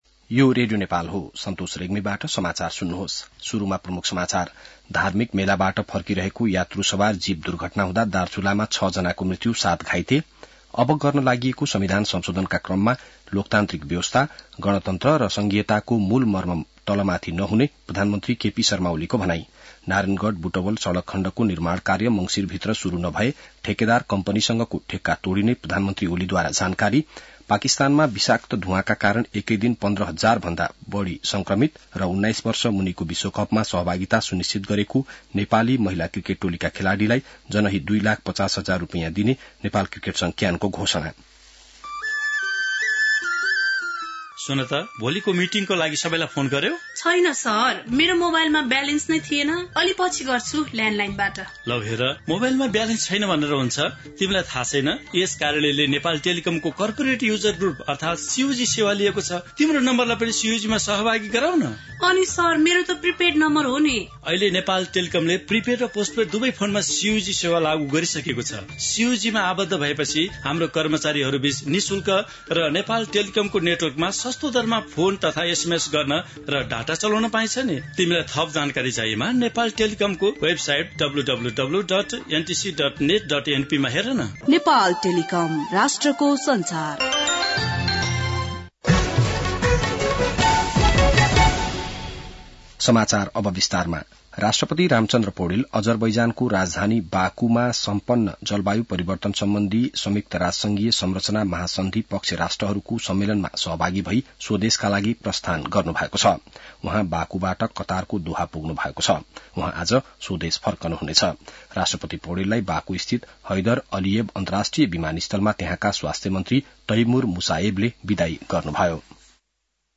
An online outlet of Nepal's national radio broadcaster
बिहान ७ बजेको नेपाली समाचार : १ मंसिर , २०८१